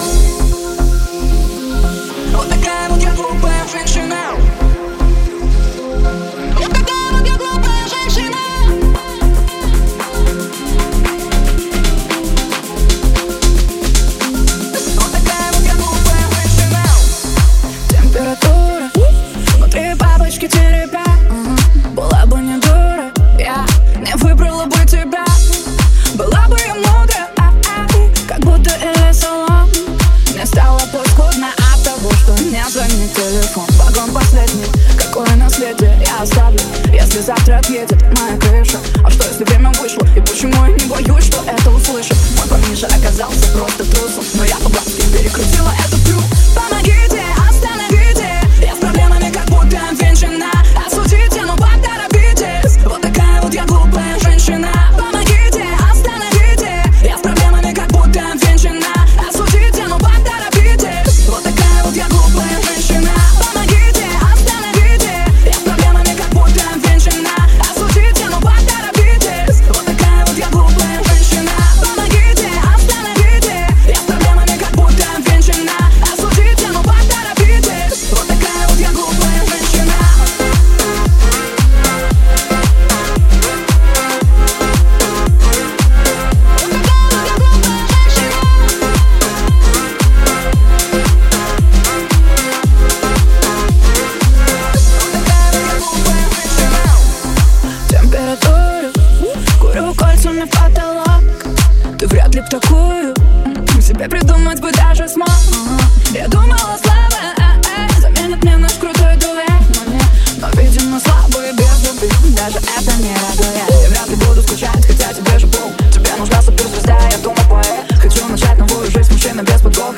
Поп-музыка